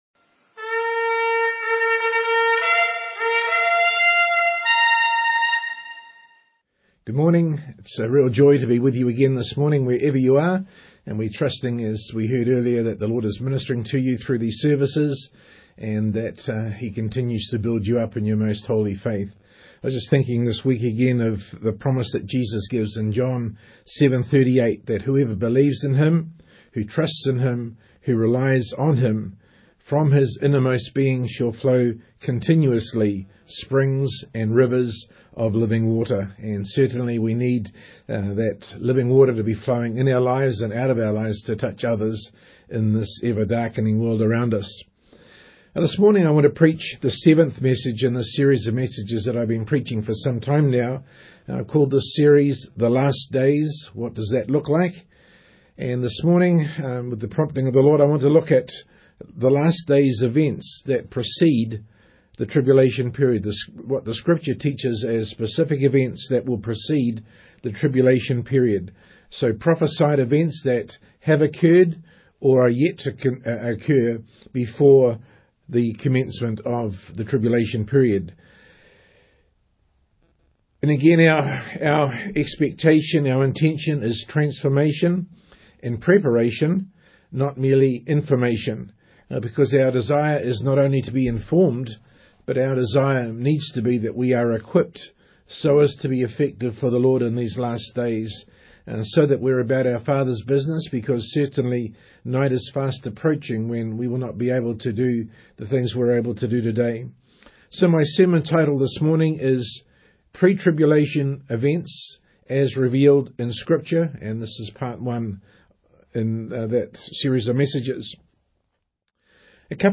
Online Sunday Morning Service 14th April,2024 Slides from this service are below.